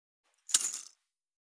374,薬を振る,薬瓶,薬瓶振る,
効果音